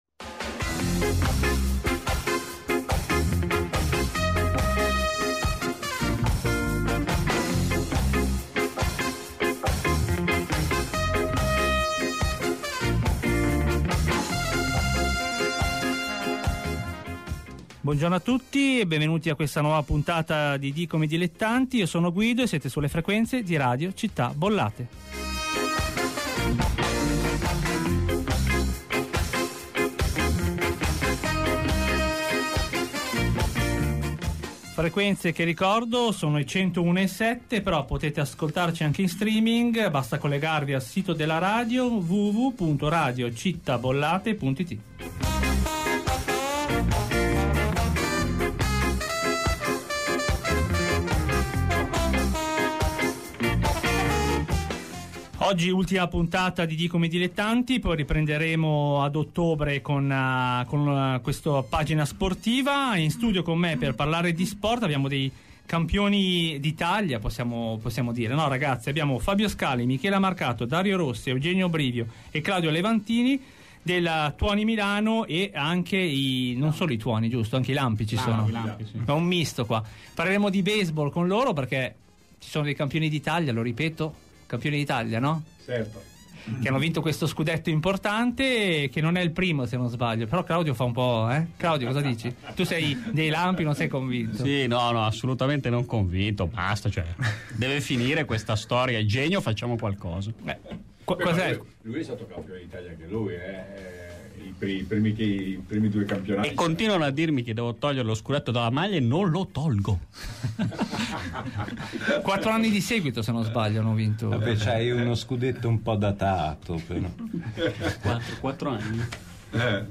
ospiti a radio città bollate